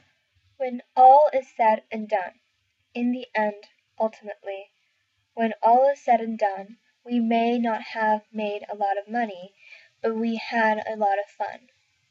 最も重要なことを伝えたい時の、前置きに用いられます。 英語ネイティブによる発音は下記のリンクをクリックしてください。